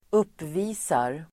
Uttal: [²'up:vi:sar]